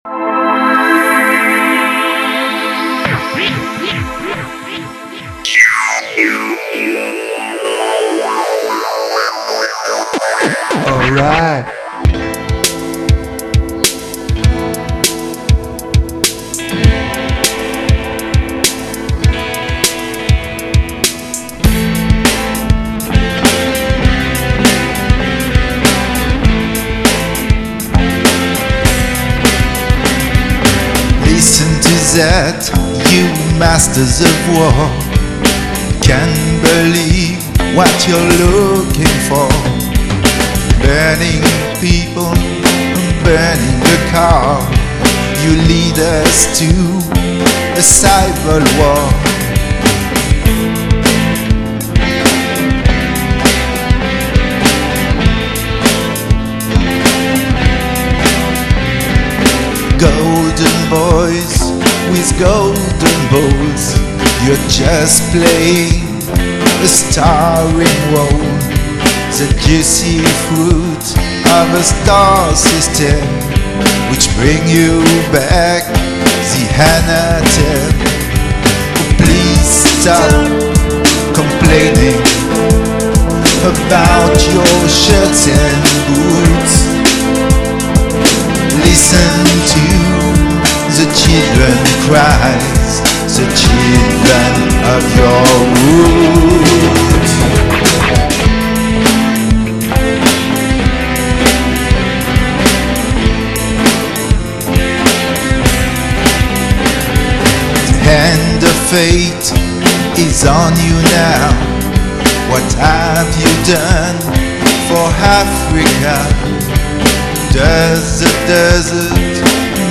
voix, claviers, guitares,basse et programmation batterie